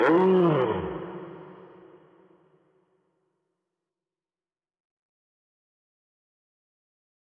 DMV3_Vox 4.wav